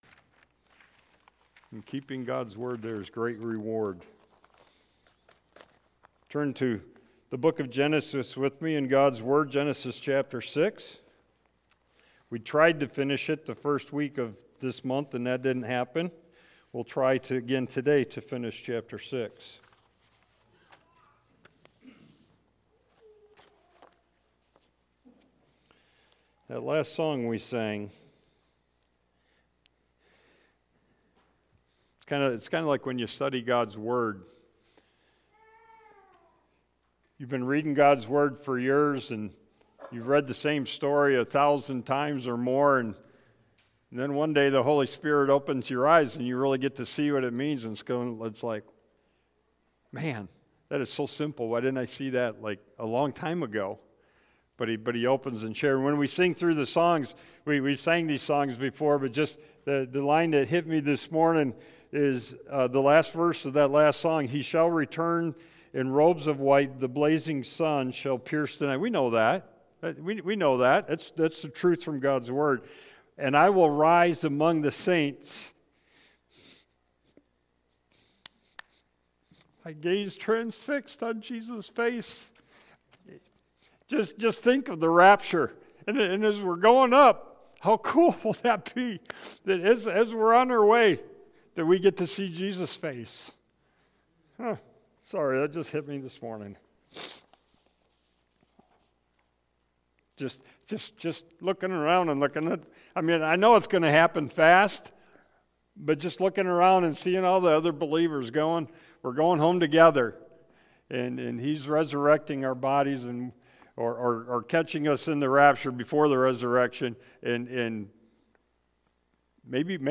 Sermons by First Baptist Church of Newberry Michigan